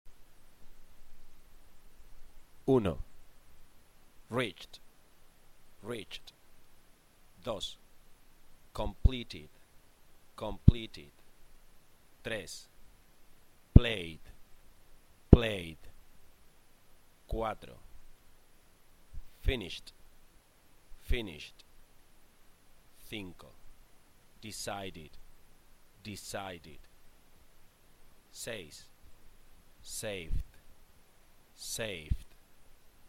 Escucha los siguientes verbos e indica 1 para los que se pronuncian /d/, 2 para los que se pronuncian /t/ y 3 para los que se pronuncian /id/. Cada verbo se repite dos veces.